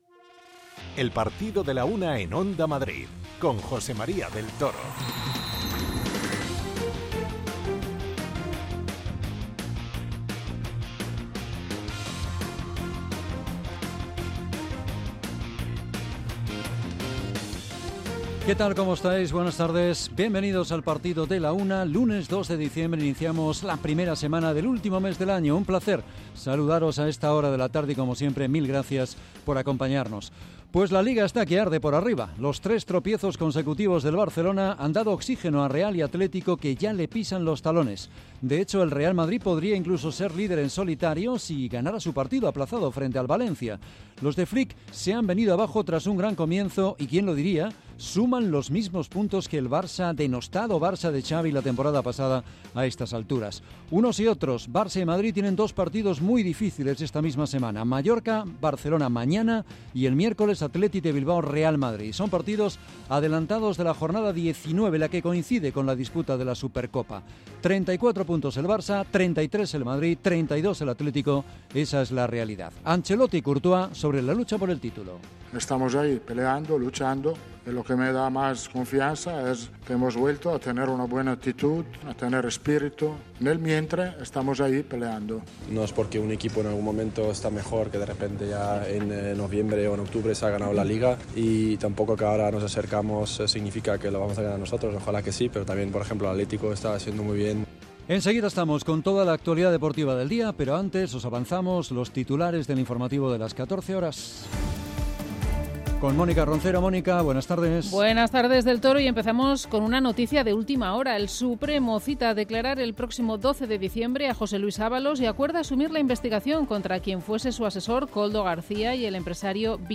Escuchamos a Ancelotti y Courtois.